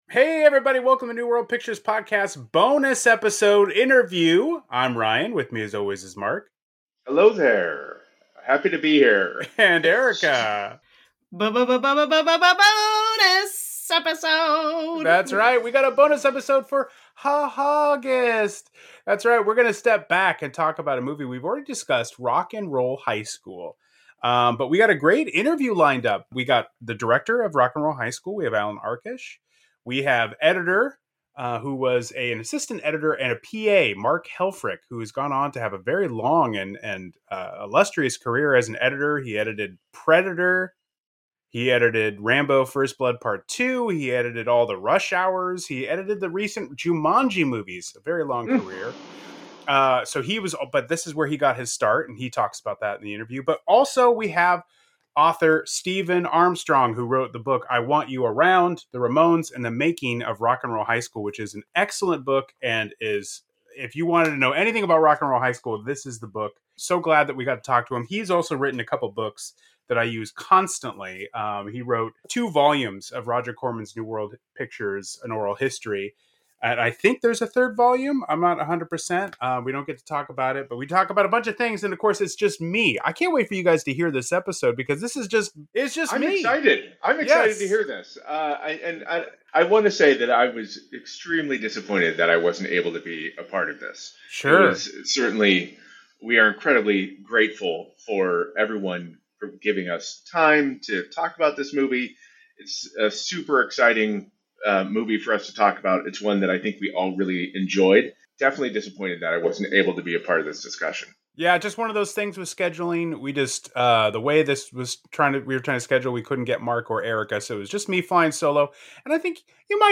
Bonus Episode: Rock 'n' Roll High School Interview with director Allan Arkush